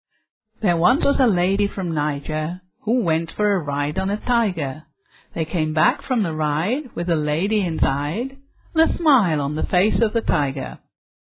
Прослушайте аудиофайл с записью лимерика и определите, ритму какой строчки из проделанного интерактивного упражнения близок ритм записанных предложений.